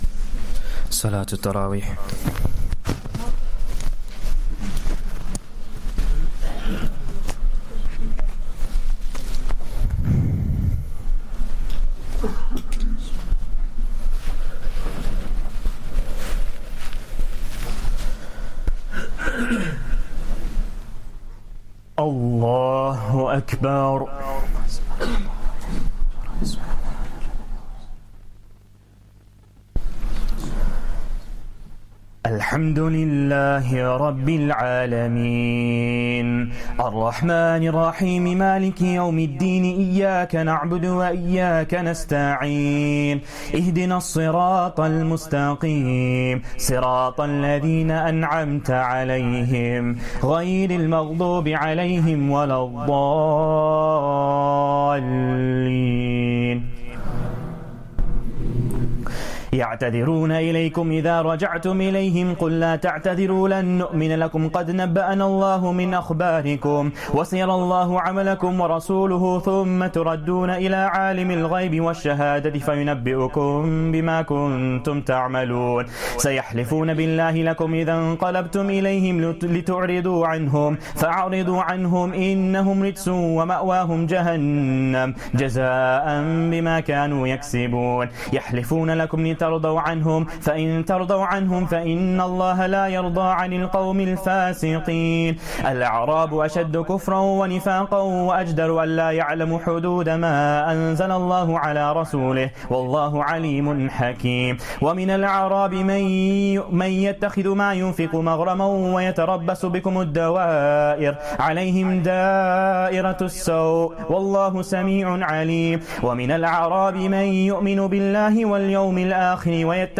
Taraweeh Prayer 9th Ramadan